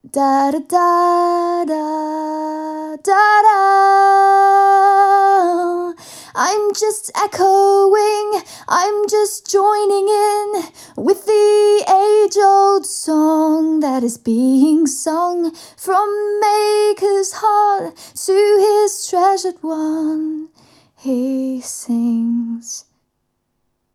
Próbki dzwiękowe Audio Technica AE-3000
Audio Technica AE3000 mikrofon - damski wokal